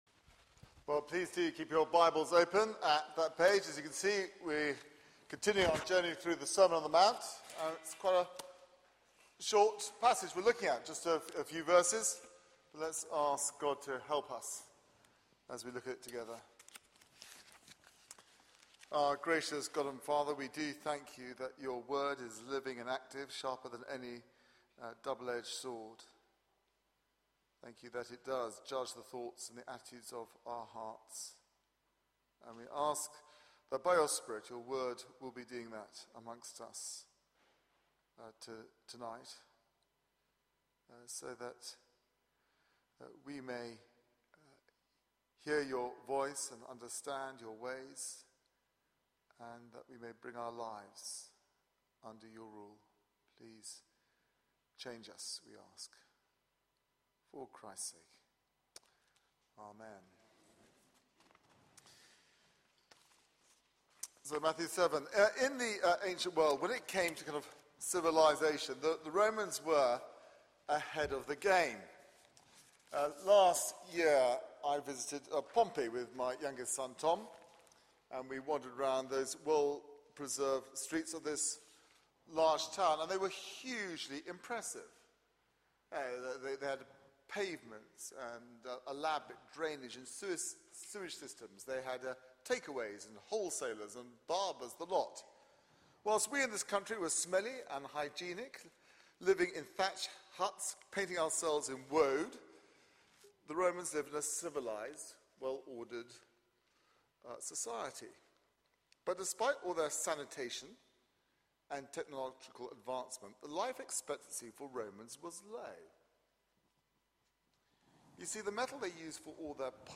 Media for 6:30pm Service on Sun 23rd Jun 2013